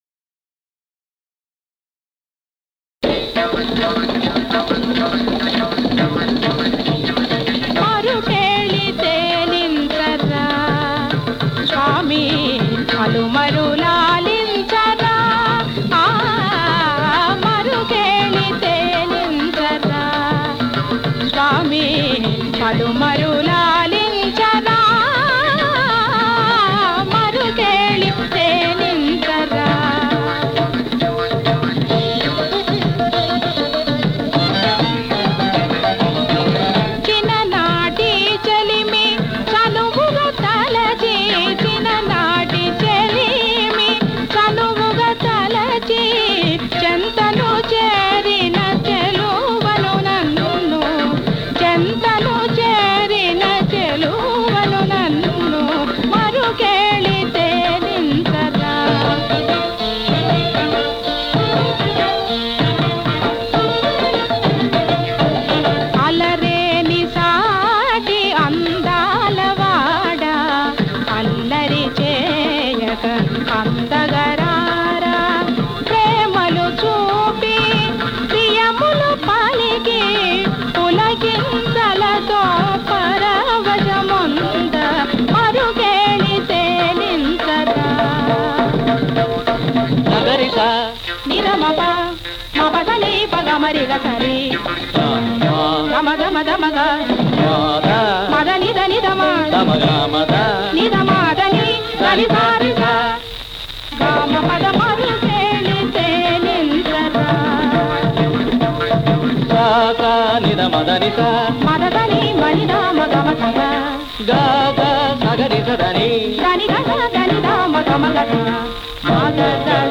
శాస్త్రీయ బాణీలో